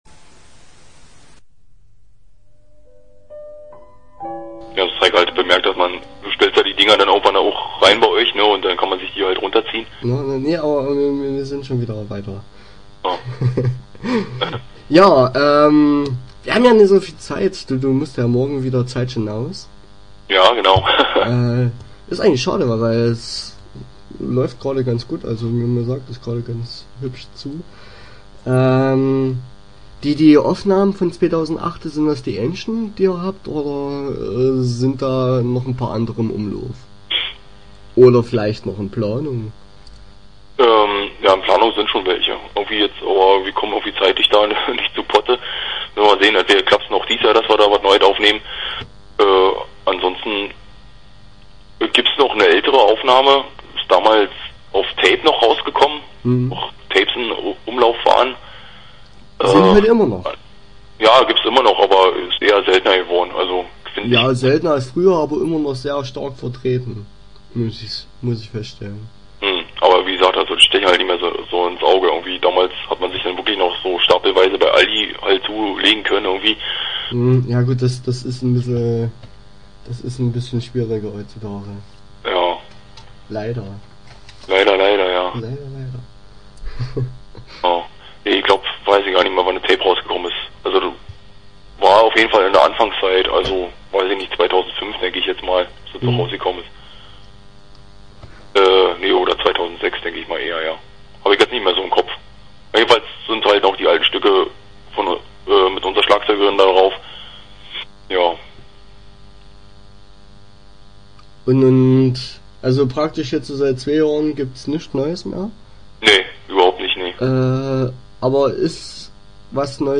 Start » Interviews » Kellertreppe